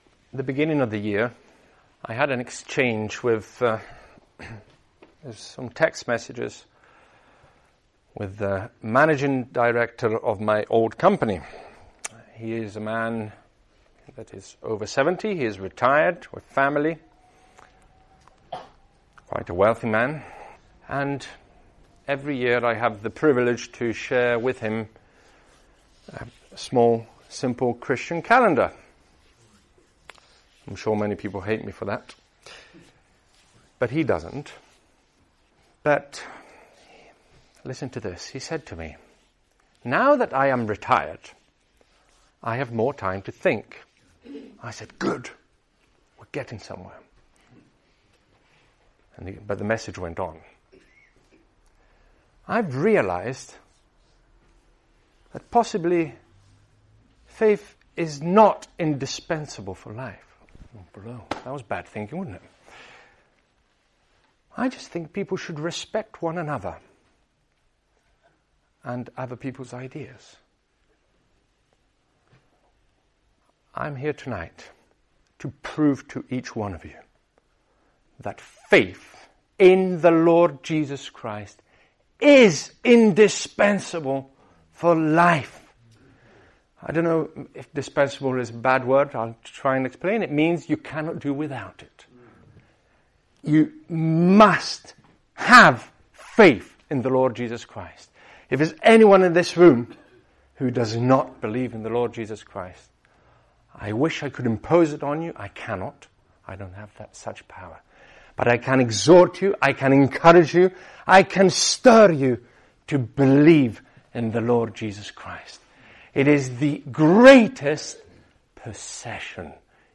This Bible teaching address explores five important blessings that come from faith in Jesus Christ. Using passages from the Scriptures, this teaching shows us how believing in Christ brings forgiveness of sins, justification before God, freedom from shame, the promise of eternal life, and guidance through the light He gives. The address highlights how faith can lead people out of spiritual darkness and into a life of hope, assurance, and purpose.